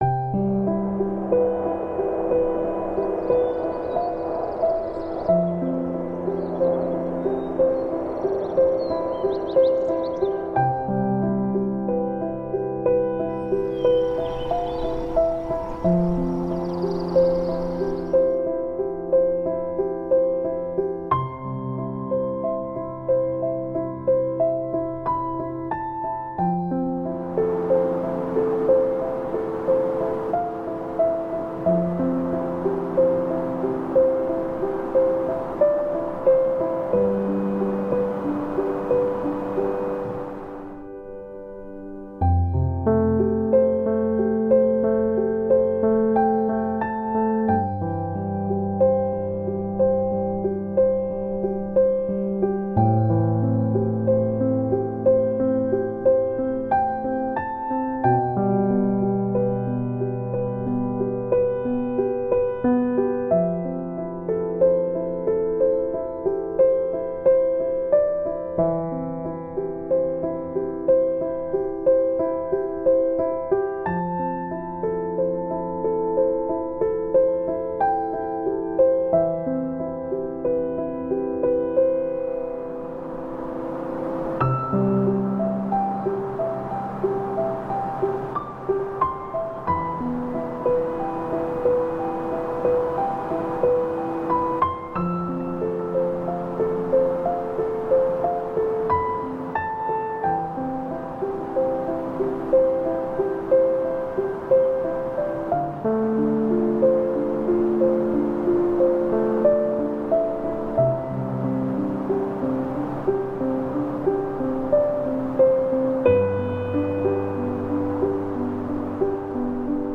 Naturgeräusche